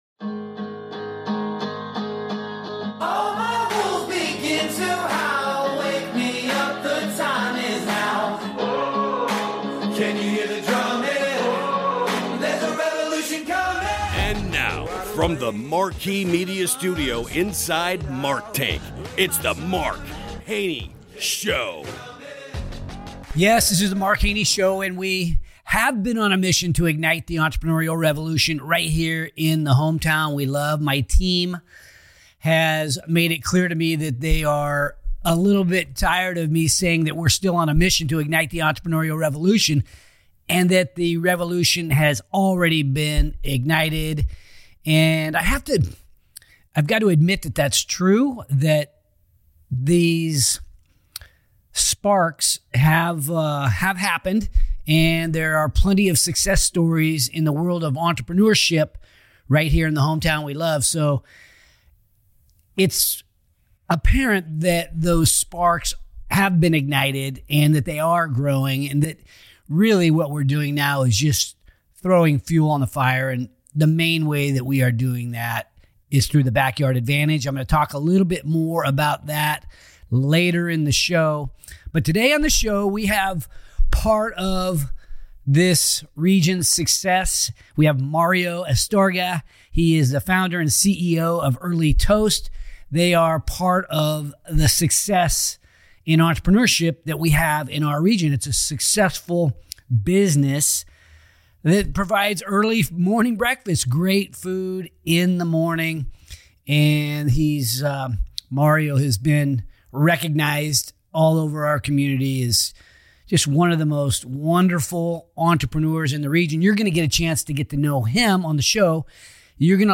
enjoy this inspirational conversation full of wisdom, hope and love.